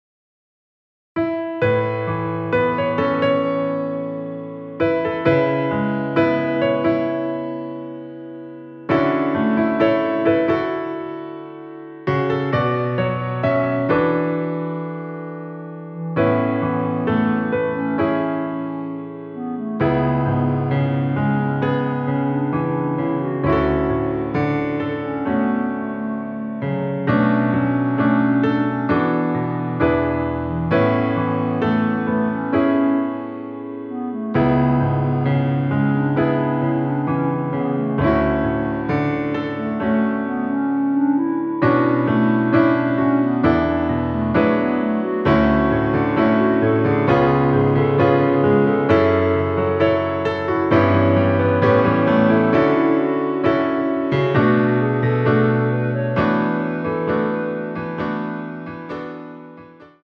원키 멜로디 포함된 MR 입니다.(미리듣기 참조)
앞부분30초, 뒷부분30초씩 편집해서 올려 드리고 있습니다.